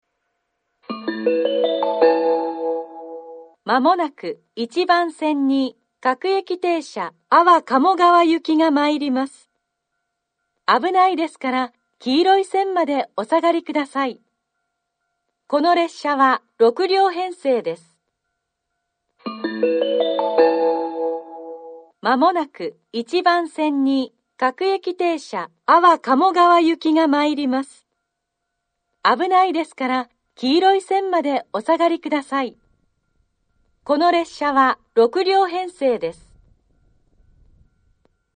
１番線接近放送 各駅停車安房鴨川行（６両）の放送です。